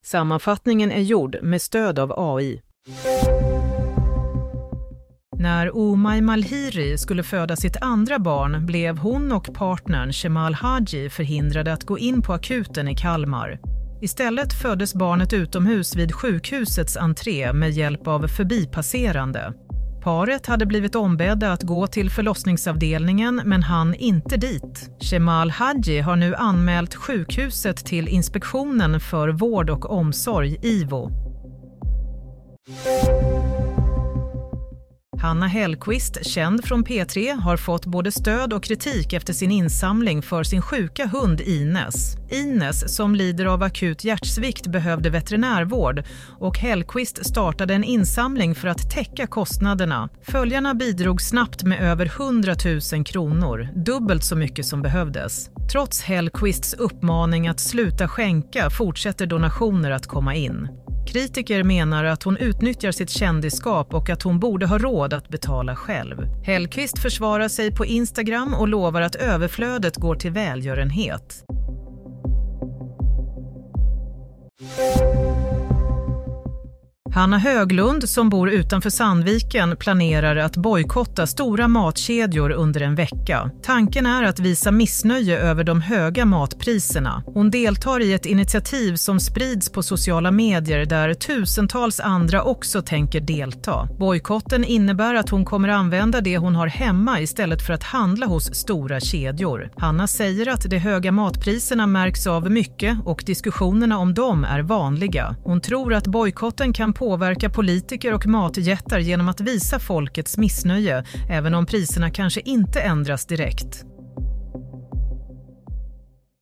Nyhetssammanfattning – 16 mars 22.00